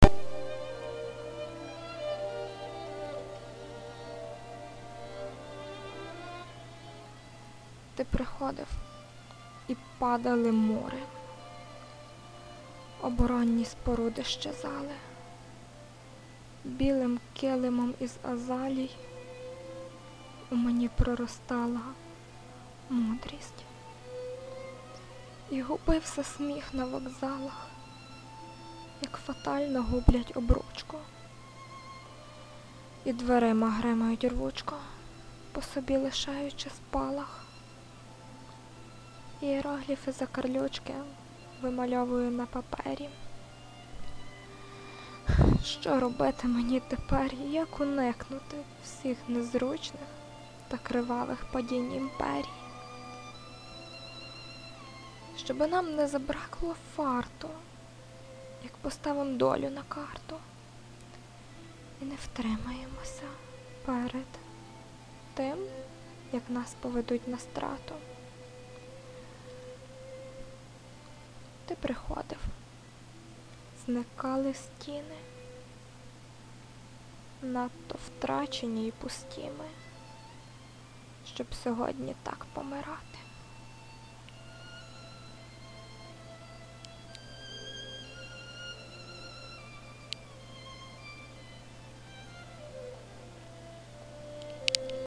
І яке фаталістичне виконання...